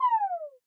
ORG Tom FX.wav